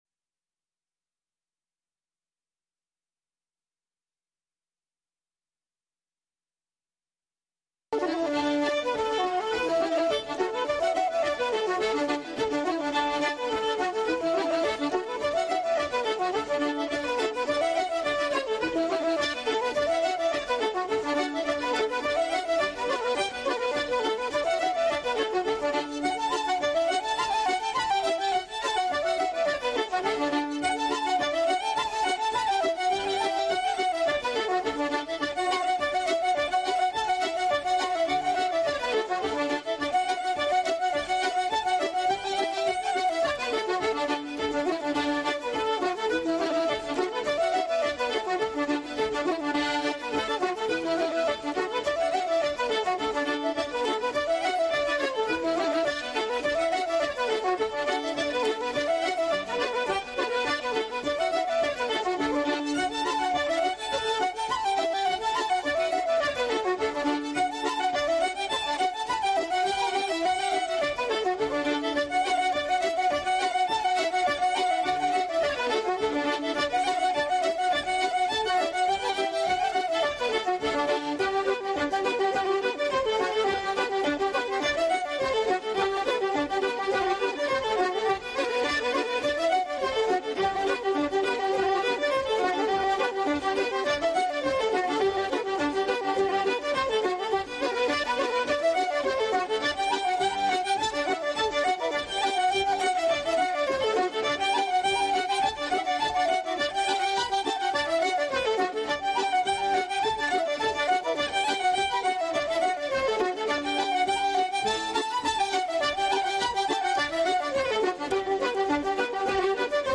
broadcasts live with music, call-ins, news, announcements, and interviews